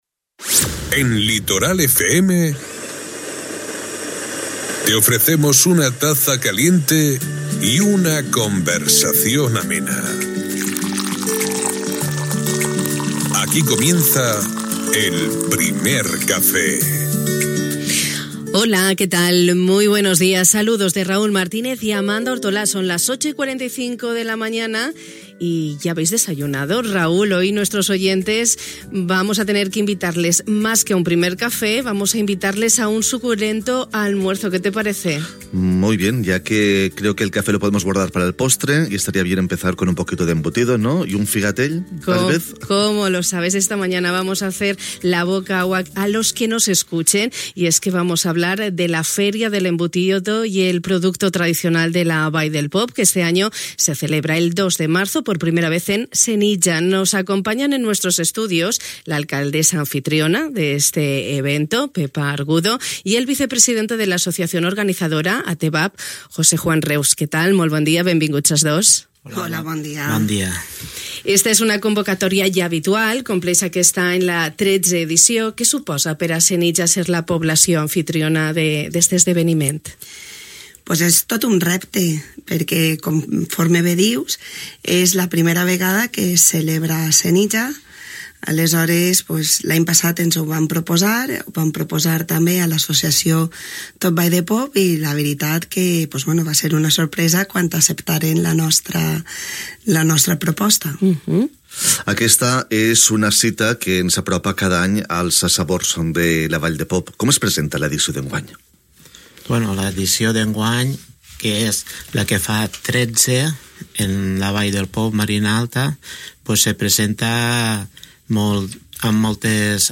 i Pepa Argudo, alcaldessa de Senija.